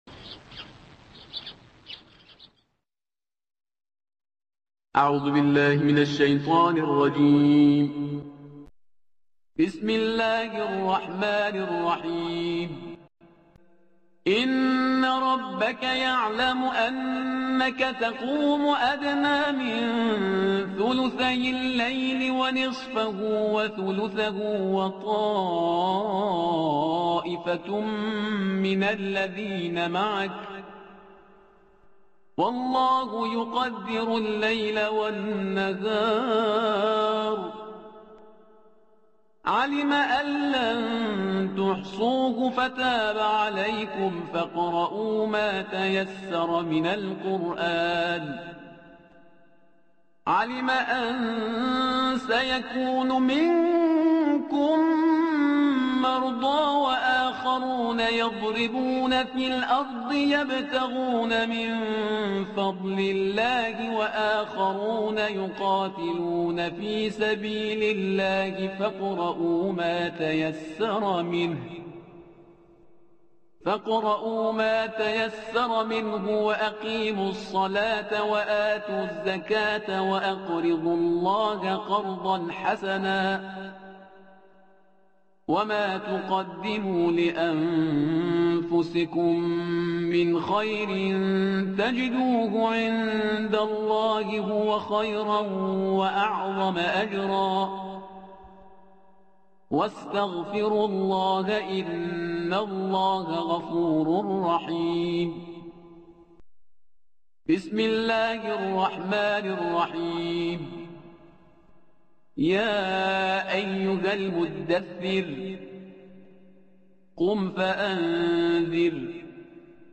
ترتیل صفحه ۵۷۵ قرآن‌کریم شامل آیه آخر سوره مبارکه «مزمل» و آیات ۱ تا ۱۷ سوره مبارکه «مدثر» با صدای استاد شهریار پرهیزکار تقدیم مخاطبان ایکنا می‌شود.
ترتیل